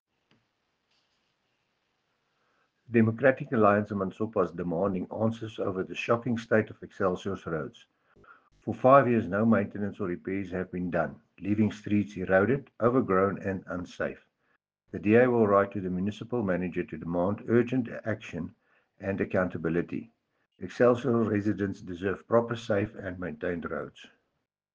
Afrikaans soundbites by Cllr Dewald Hattingh and